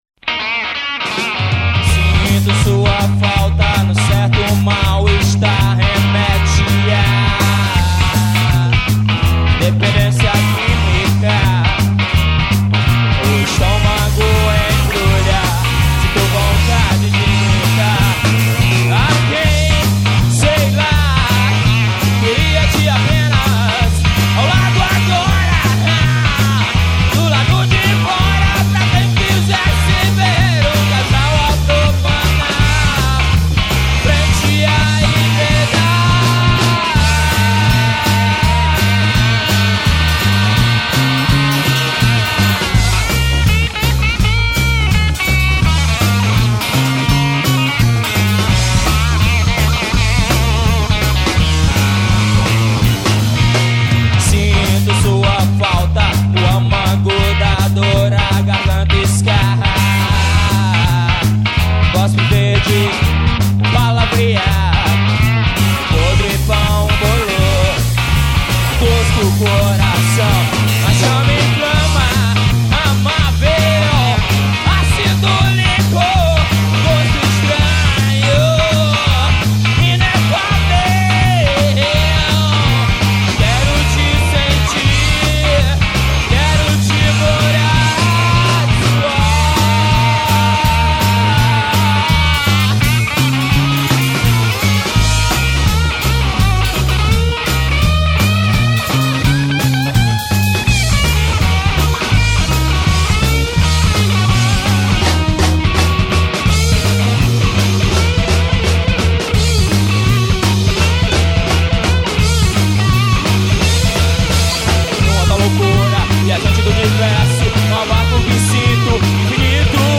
1722   02:40:00   Faixa:     Rock Nacional